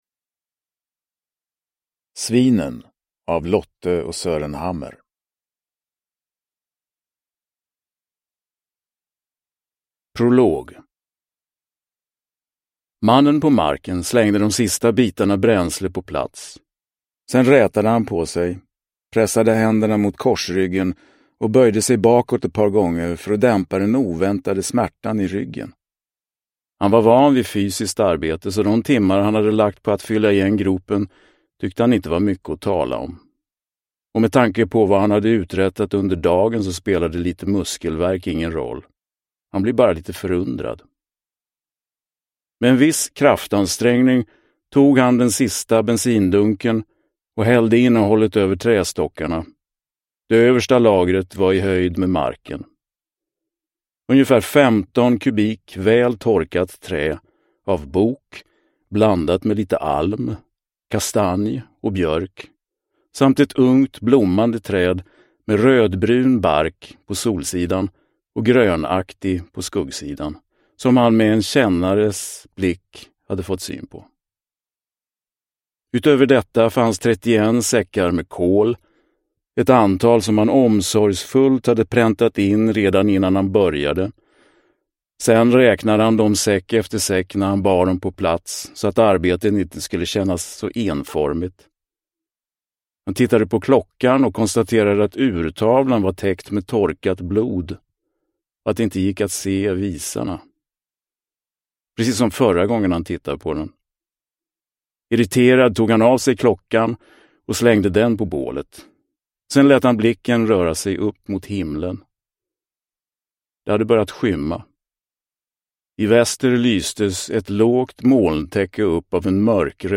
Svinen – Ljudbok – Laddas ner